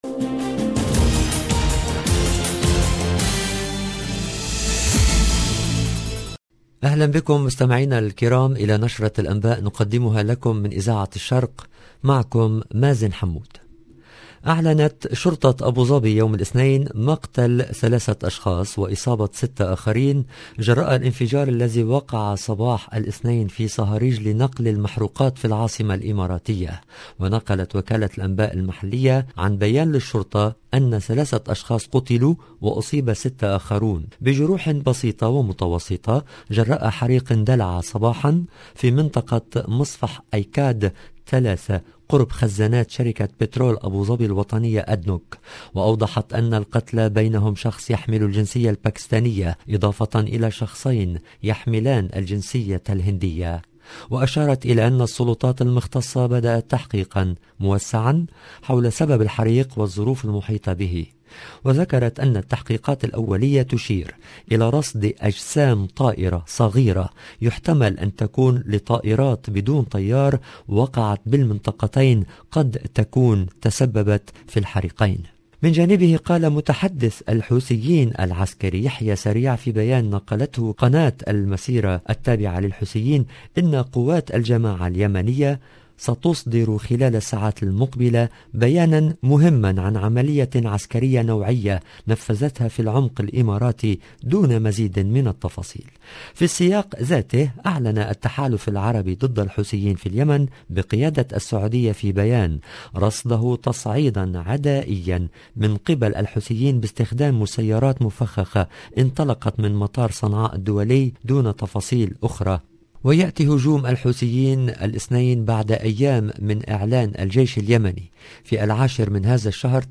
LE JOURNAL DU SOIR EN LANGUE ARABE DU 17/01/22